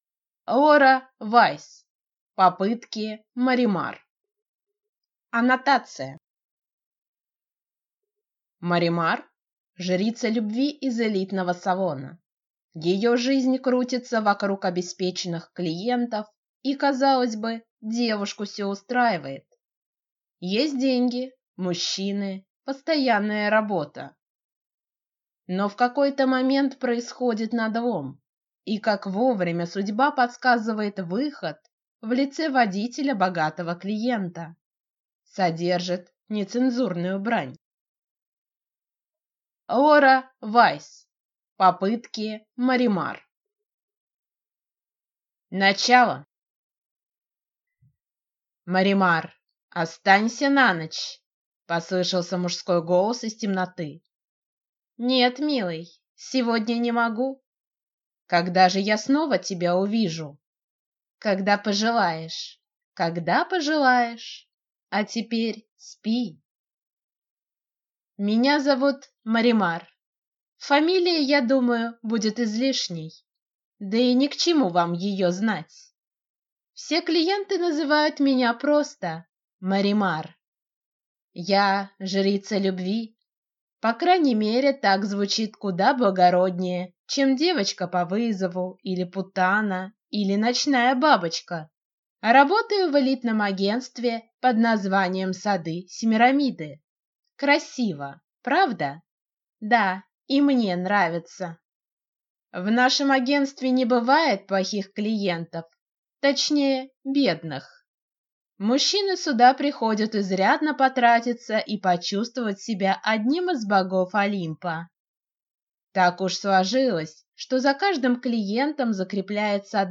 Аудиокнига Попытки Маримар | Библиотека аудиокниг
Прослушать и бесплатно скачать фрагмент аудиокниги